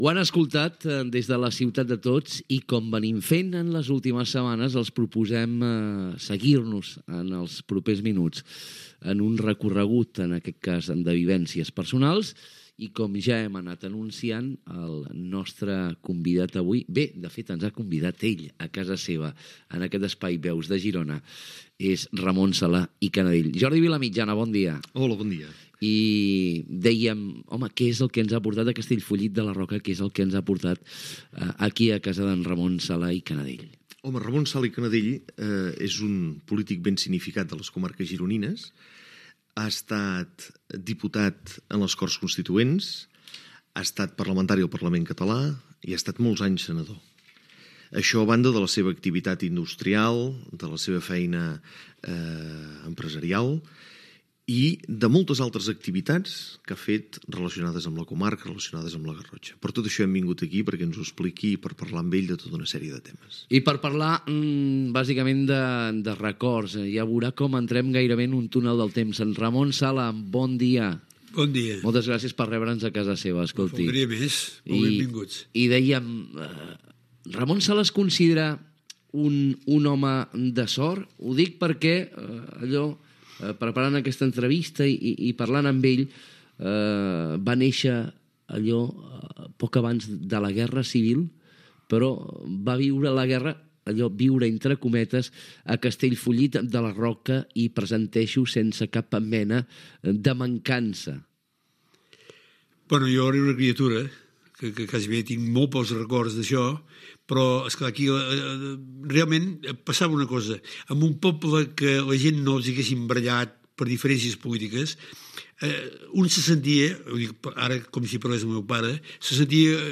Presentació i inici de l'entrevista a Ramon Sala i Canadell, polític i industrial, a la seva casa de Castellfollit de la Roca